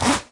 描述：我打开/关闭牛仔裤上的苍蝇。
Tag: 裤子 拉链 拉链